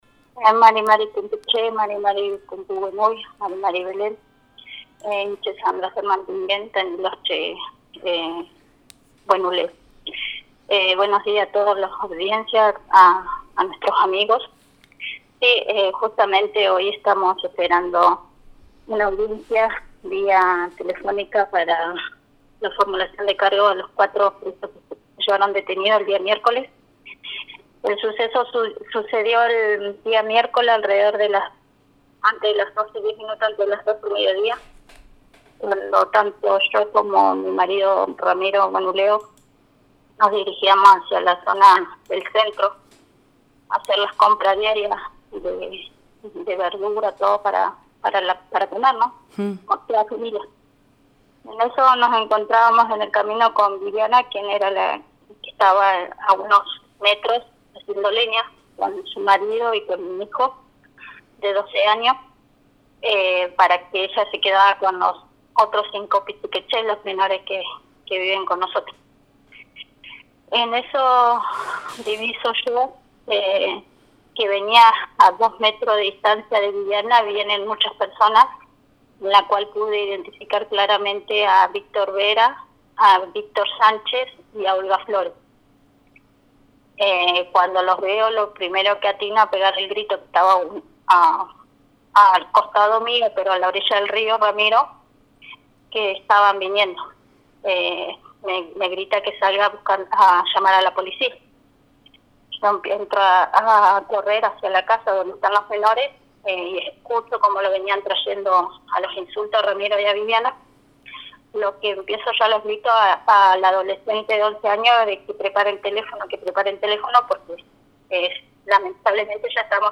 Escuchá el testimonio completo: https